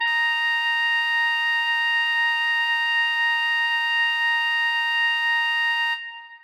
Playback sounds of Bb Clarinet having this weird glitchy sound?
One of the specific notes in which a "glitchy" sound can be heard is C6 on B-flat clarinet. I've found out the glitchy sound mostly occurs during high notes (past C5) played at louder dynamics (forte, fortissimo etc.)
Below are recordings of the C6 note in MuseScore 4 and MuseScore 3.
C6_BbClarinet_Musescore4_0_0.ogg